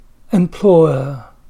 sound_loud_speaker employer  /emˈplɔə/
employer-smoothed.mp3